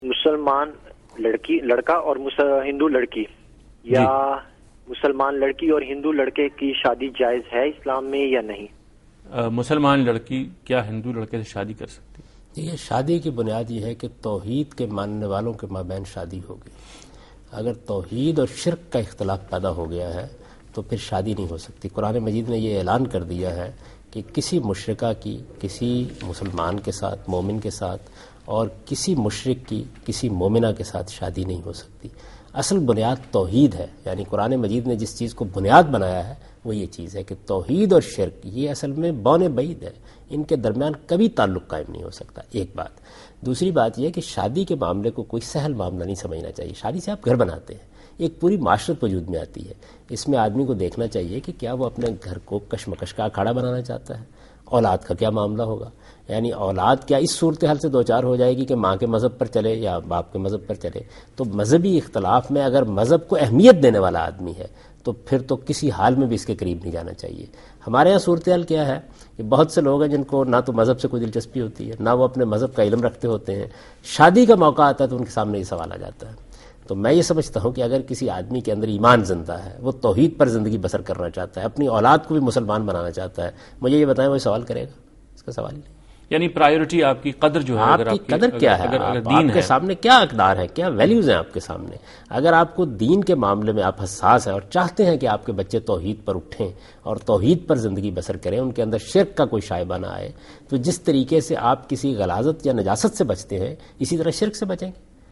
Answer to a Question by Javed Ahmad Ghamidi during a talk show "Deen o Danish" on Duny News TV
دنیا نیوز کے پروگرام دین و دانش میں جاوید احمد غامدی ”ہندوؤں کے ساتھ شادی“ سے متعلق ایک سوال کا جواب دے رہے ہیں